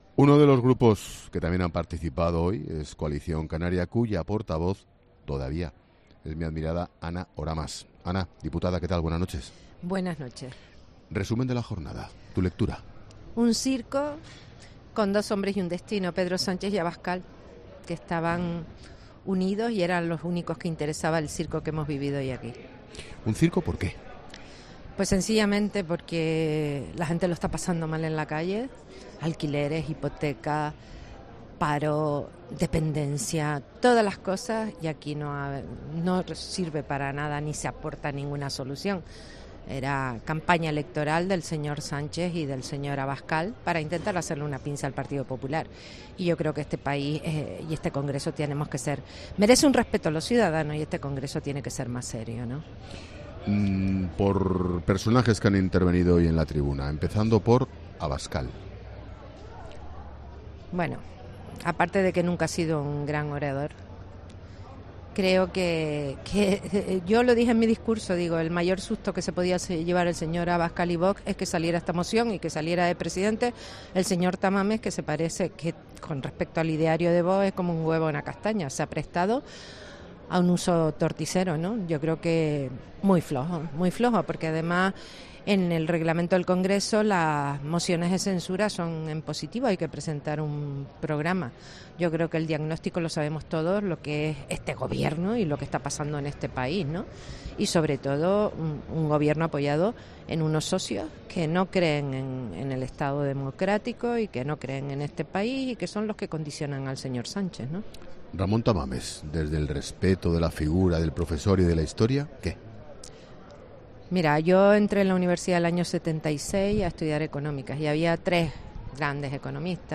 La diputada por Coalición Canaria en el Congreso de los Diputados, Ana Oramas, analizaba este martes en La Linterna, precisamente a las puertas del Parlamento, las intervenciones de los cuatro protagonistas de la primera jornada de debate de la moción de censura: Sánchez, Tamames, Abascal y Yolanda Díaz.